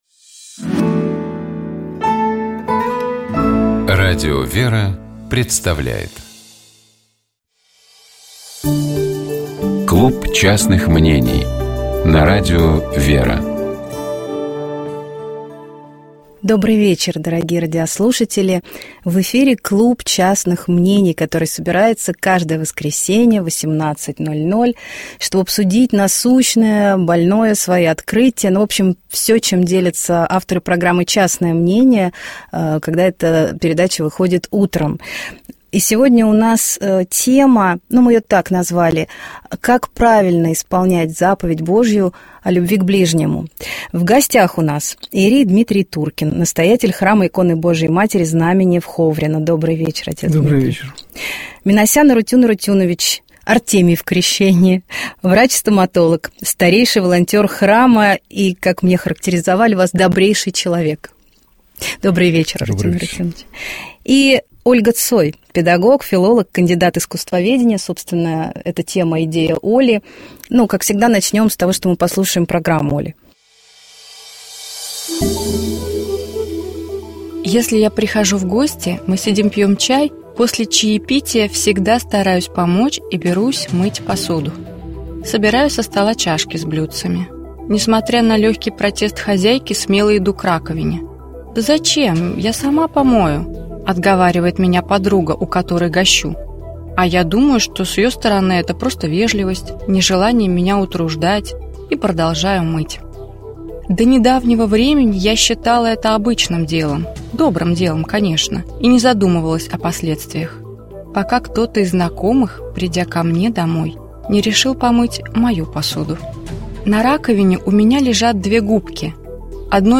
Богослужебные чтения - Радио ВЕРА